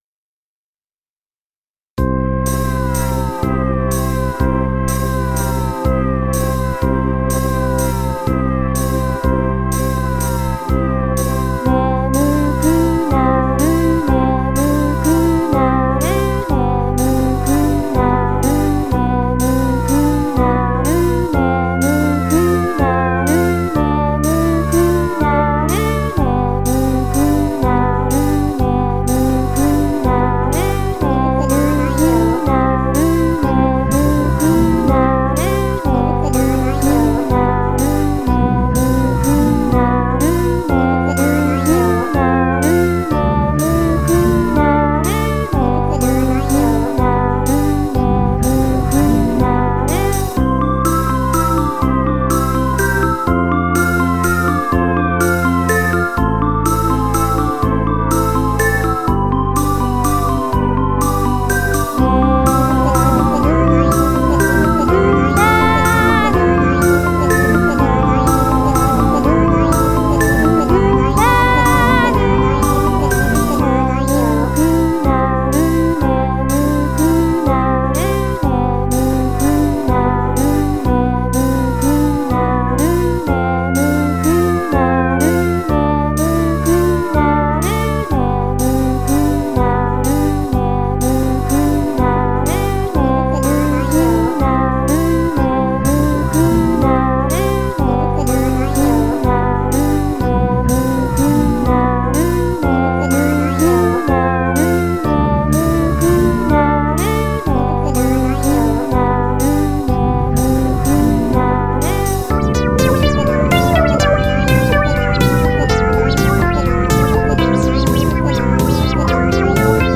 使用機材は、MEIKO,CronoX3,Synth1,Alpha,RMIII,SC-88Proです。